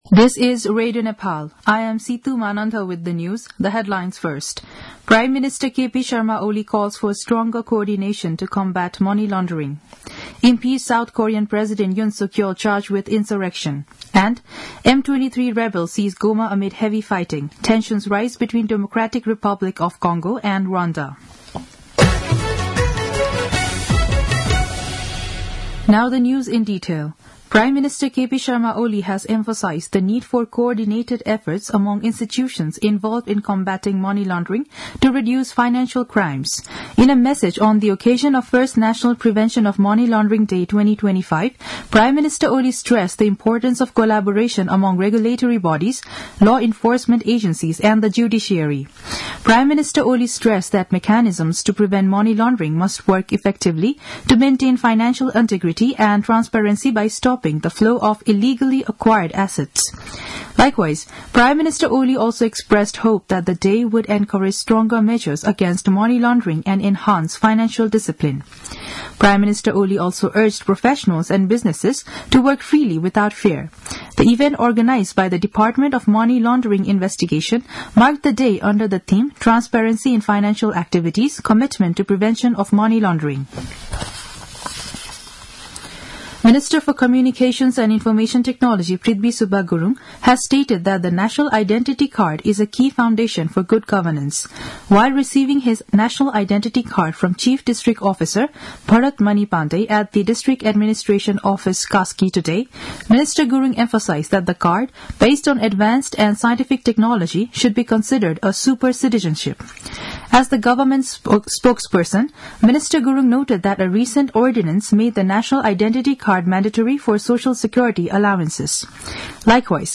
दिउँसो २ बजेको अङ्ग्रेजी समाचार : १५ माघ , २०८१